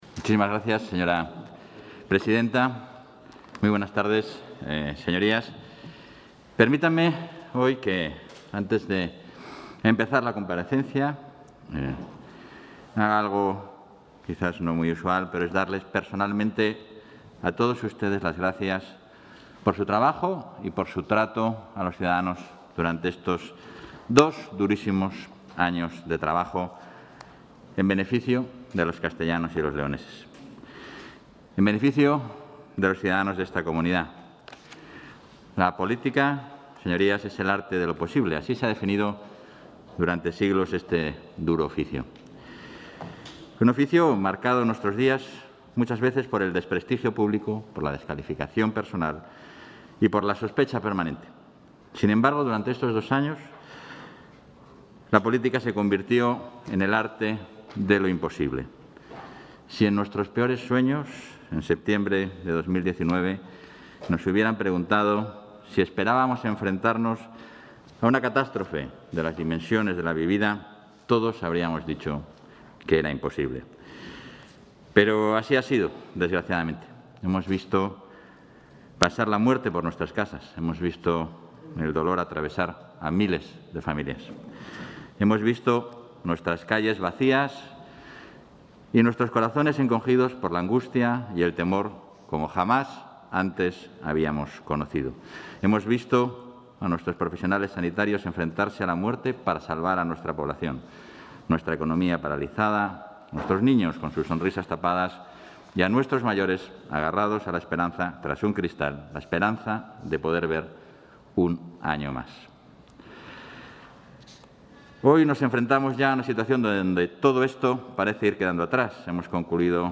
El vicepresidente Igea ha afirmado esta tarde, en su comparecencia en las Cortes de Castilla y León, que la agenda de regeneración...
Intervención del vicepresidente de la Junta.